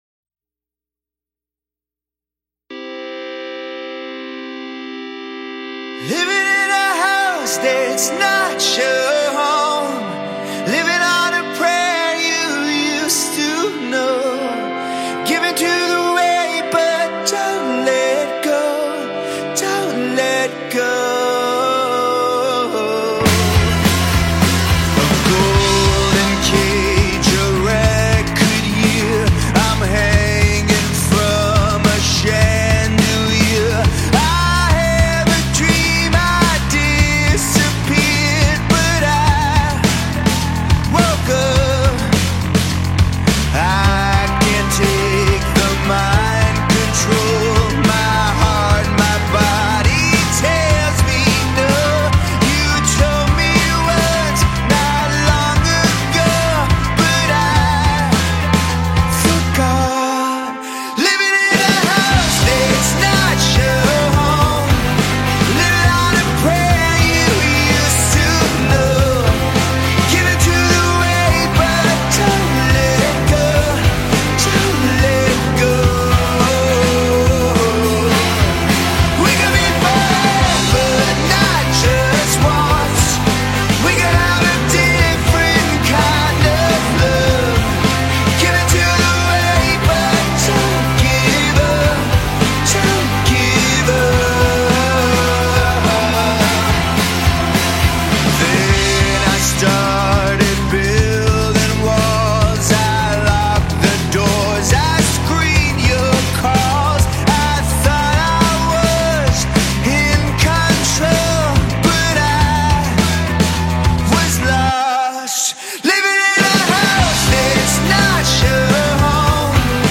This is not a grand, cinematic declaration.